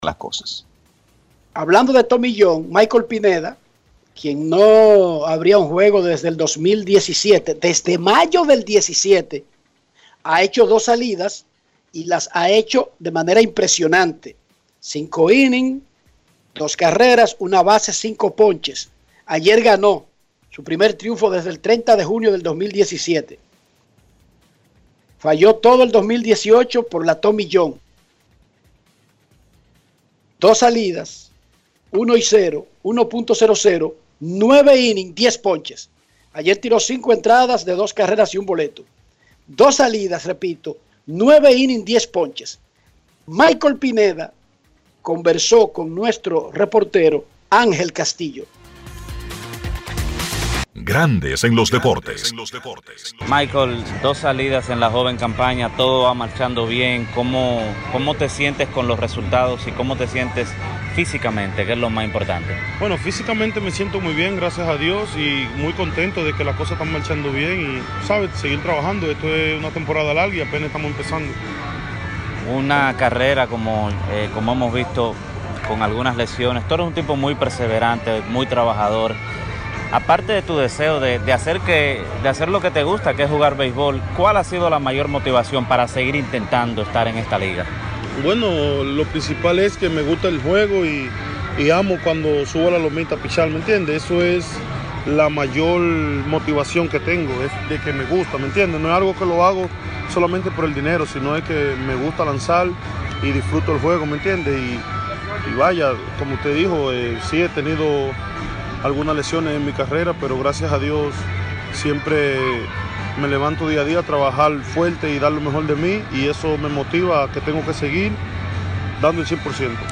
para el programa de radio Grandes en los Deportes